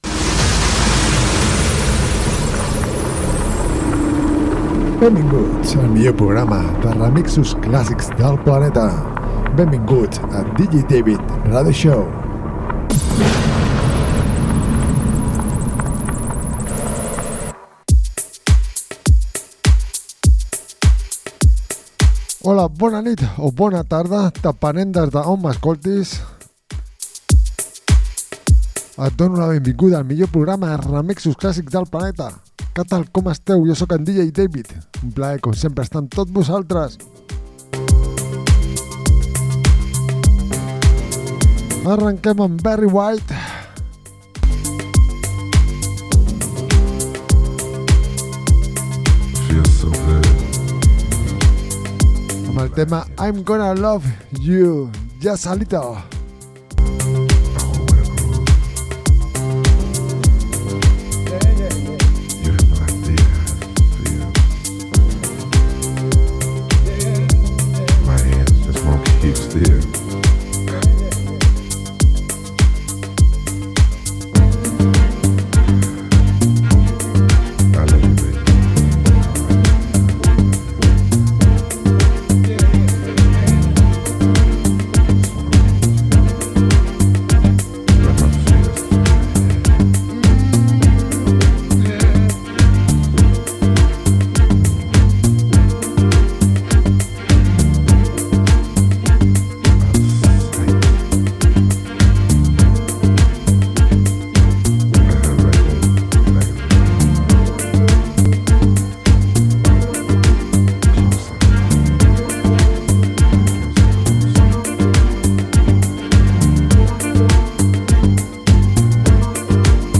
programa de ràdio
remixos classics